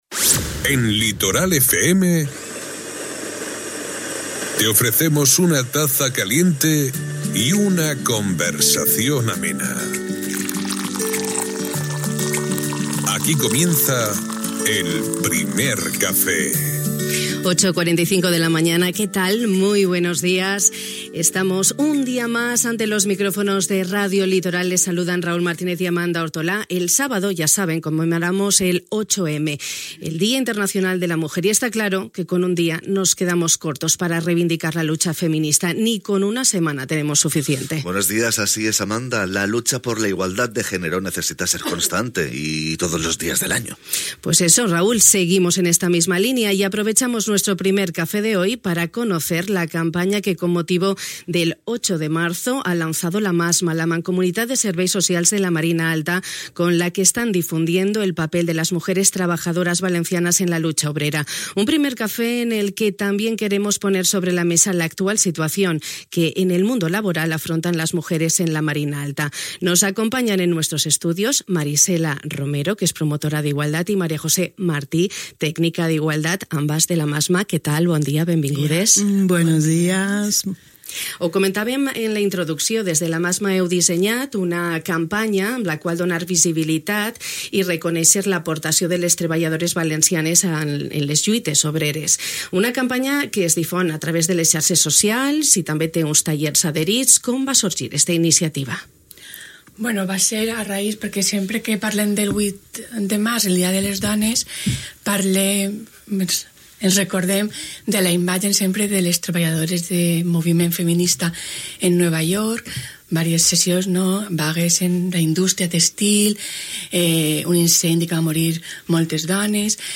Aquest matí hem aprofitat el nostre Primer Cafè per conèixer la campanya que amb motiu del 8M, Dia Internacional de la Dona, ha llançat la Massma, la Mancomunitat de Serveis Socials de la Marina Alta, amb la que estan difonent el paper de les dones treballadores valencianes en la lluita obrera. Un Primer Cafè que també ens ha servit per posar sobre la taula l'actual situació que afrontem les dones al món laboral.